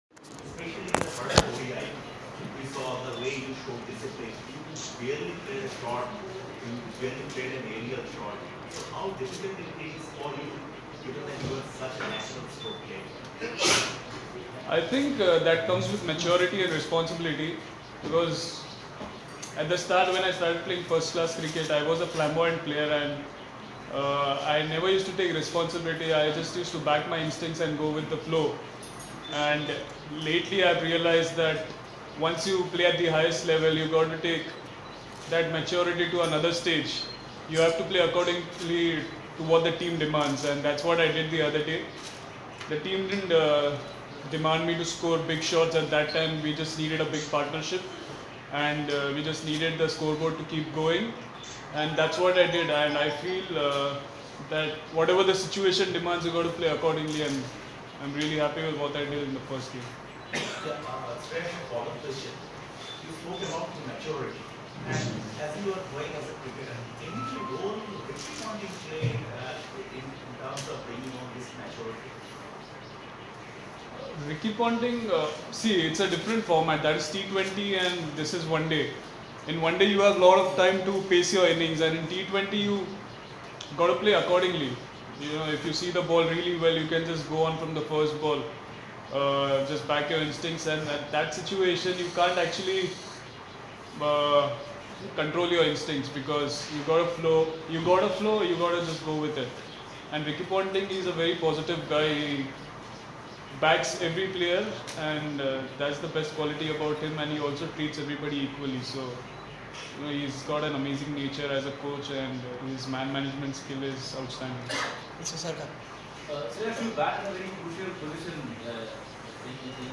Shreyas Iyer spoke to the media at Barabati Stadium, Cuttack ahead of the 3rd Paytm ODI against West Indies.